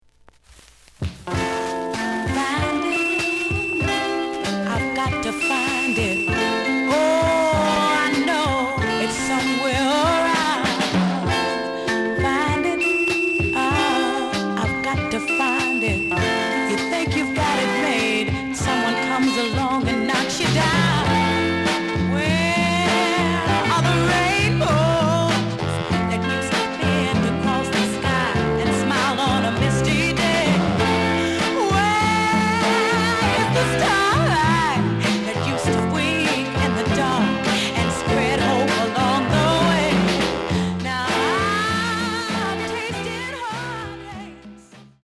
The audio sample is recorded from the actual item.
●Genre: Soul, 60's Soul
Some noise on B side.